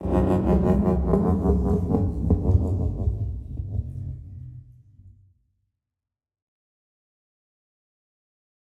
creak3.ogg
Minecraft Version Minecraft Version latest Latest Release | Latest Snapshot latest / assets / minecraft / sounds / ambient / nether / warped_forest / creak3.ogg Compare With Compare With Latest Release | Latest Snapshot